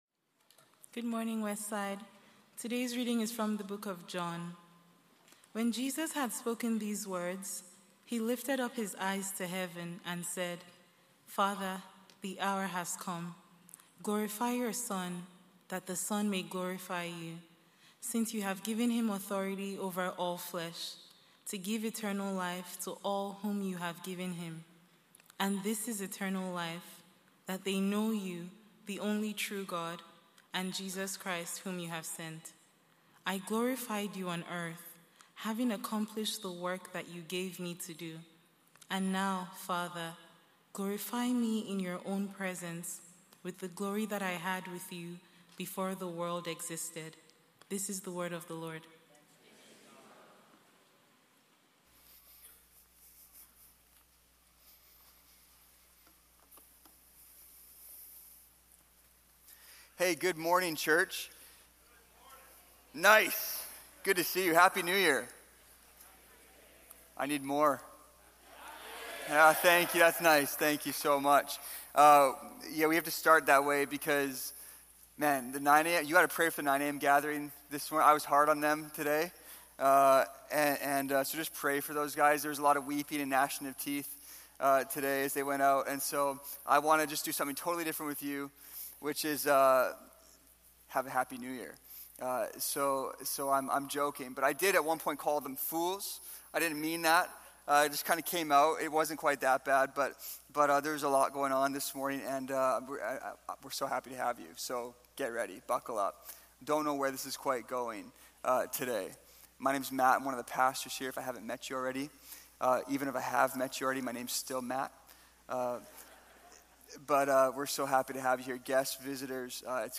1 Sunday Service 44:36